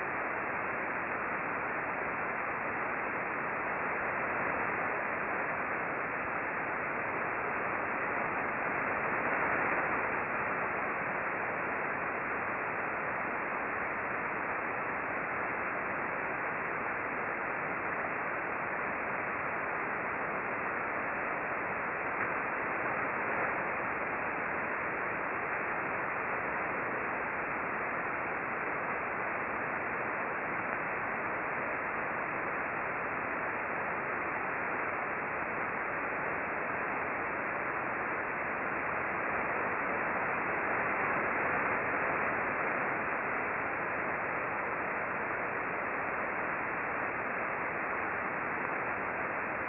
We used two Icom R-75 HF Receivers, one tuned to 19.475 MHz (LSB), corresponding to the Red trace in the charts below, and the other tuned to 24.229 MHz (LSB), corresponding to the Blue trace.
The antenna was an 8-element log periodic antenna pointed about 91 degrees true (no tracking was used).
We observed L-bursts during the brief periods of the audio recordings. The charts below show the two brief reception periods of low-level bursting with expanded time scales.
Click the chart above for a mono audio file corresponding to the red trace (19.475 MHz)